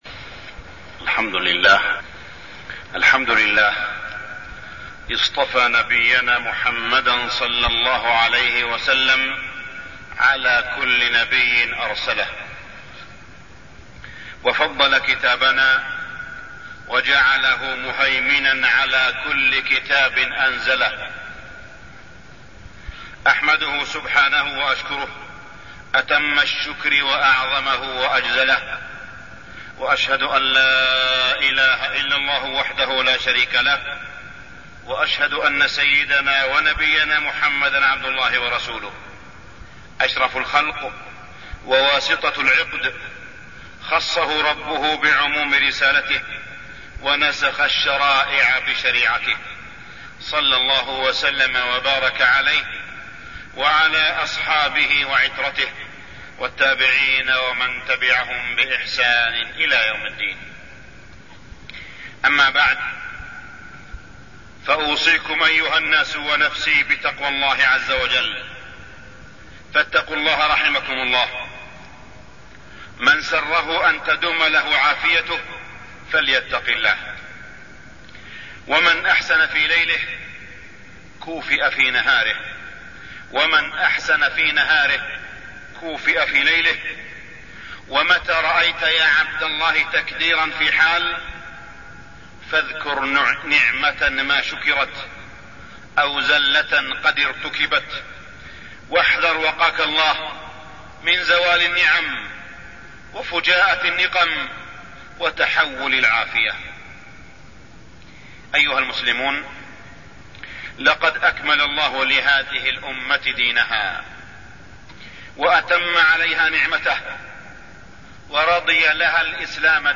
تاريخ النشر ١١ ربيع الأول ١٤٢٠ هـ المكان: المسجد الحرام الشيخ: معالي الشيخ أ.د. صالح بن عبدالله بن حميد معالي الشيخ أ.د. صالح بن عبدالله بن حميد فضائل النبي صلى الله عليه وسلم